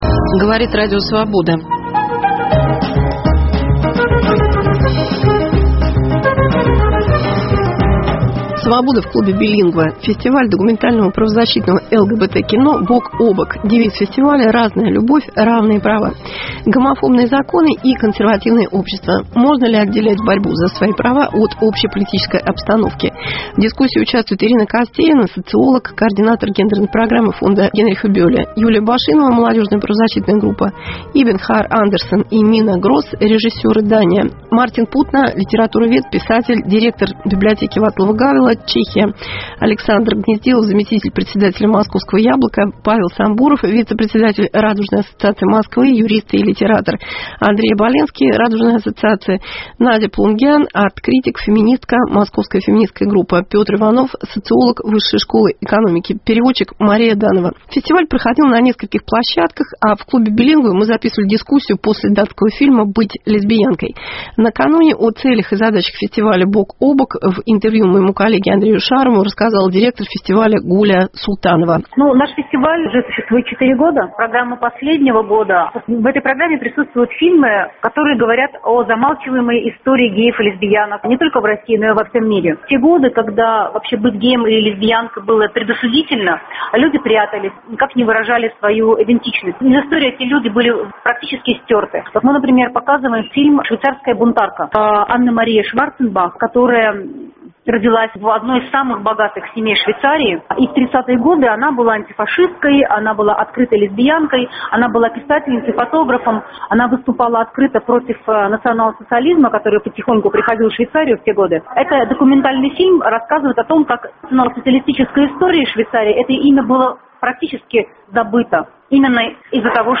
Cвобода в клубе Билингва. Права сексуальных меньшинств.